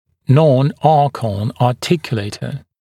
[ˌnɔn’ɑːkɔn ɑːˈtɪkjuleɪtə][ˌнон’а:кон а:ˈтикйулэйтэ]артикулятор типа Non-Arcon (не соответствует строению черепа, кондилярные части на верхнем элементе)